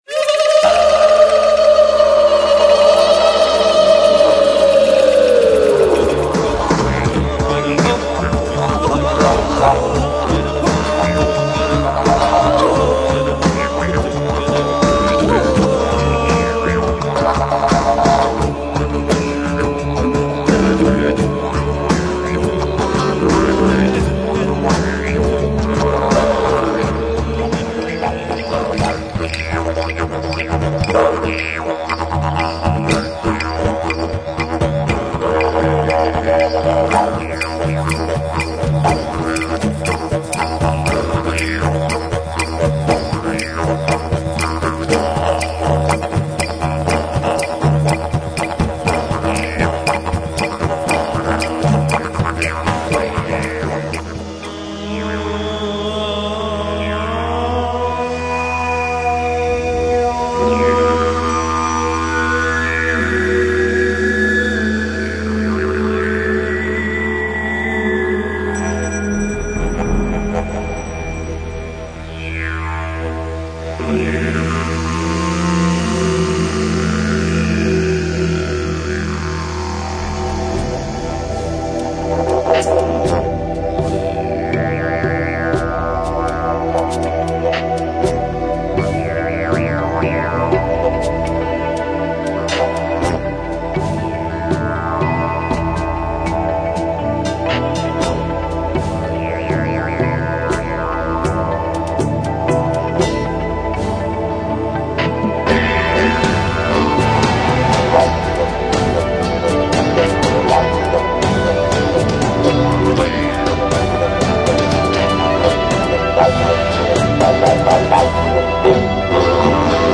multi-instrumental music